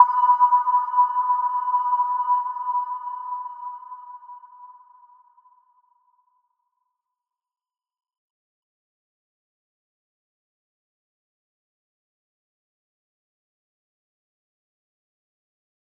Little-Pluck-B5-f.wav